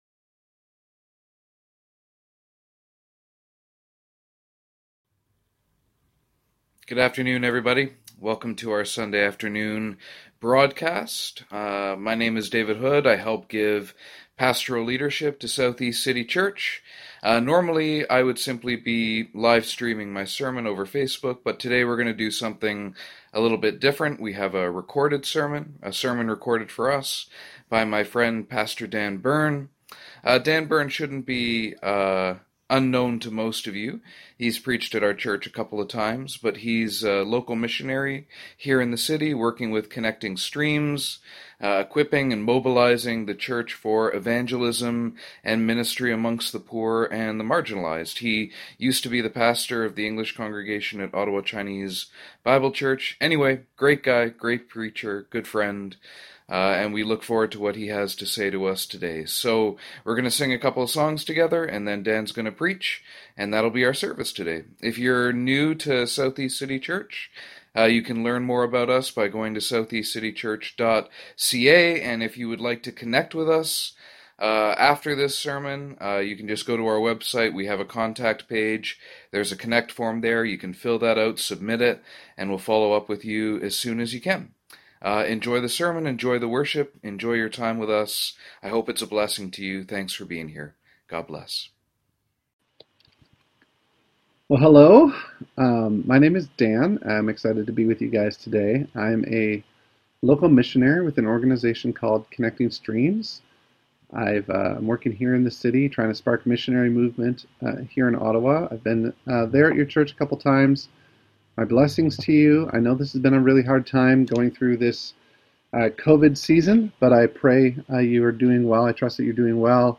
Bible Text: 1 Corinthians 8:1-13 | Preacher